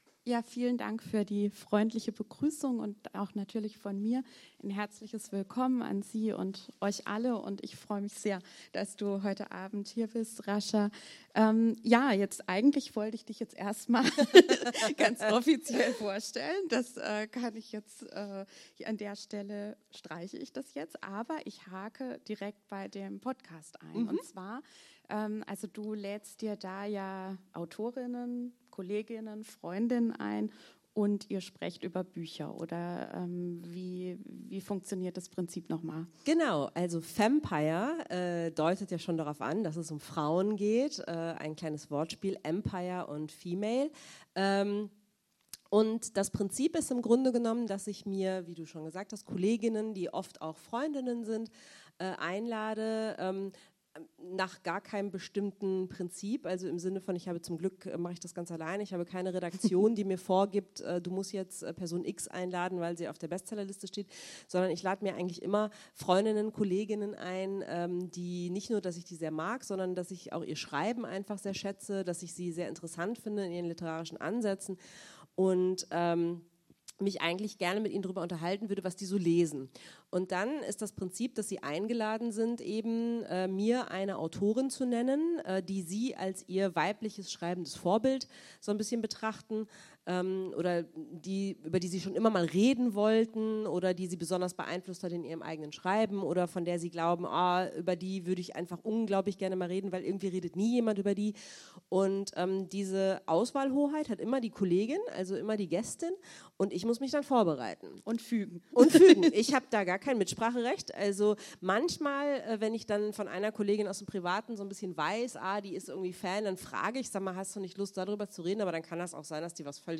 Lesung
Audio-Mitschnitt der Veranstaltung (Dauer: 1:12 h): MP3 in neuem Fenster öffnen | Download MP3 (81 MB)